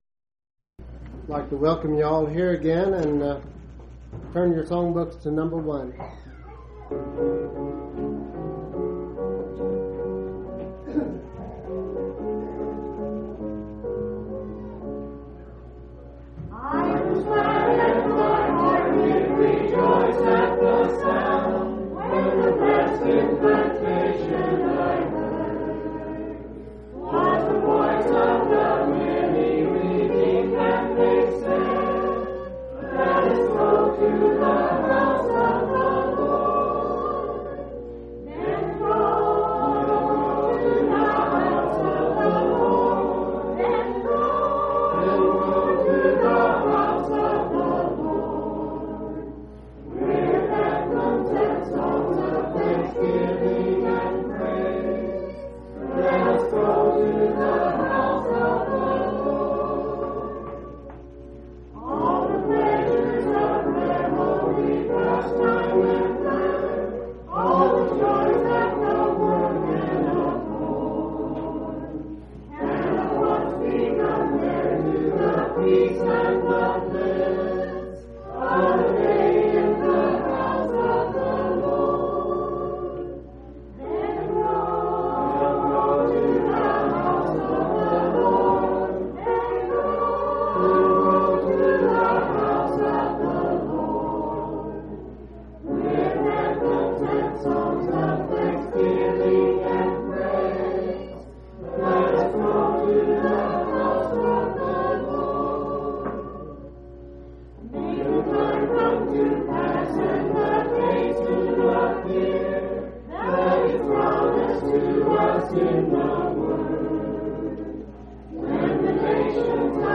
6/12/1992 Location: Colorado Reunion Event: Colorado Reunion